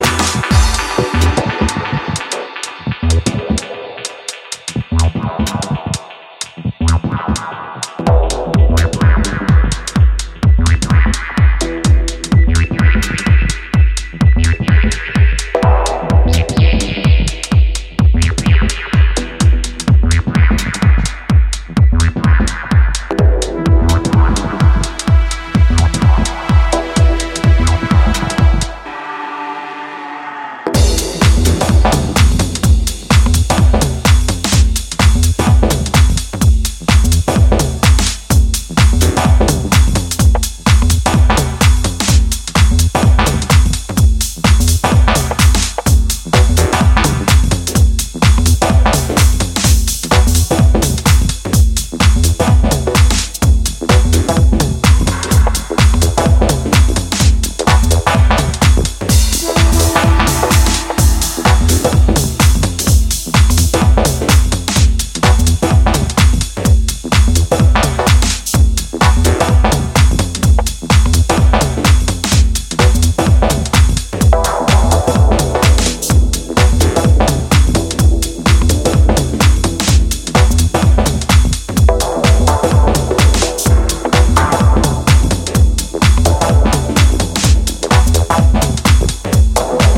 鋭いリズムワークと覚醒リフの応酬で刺す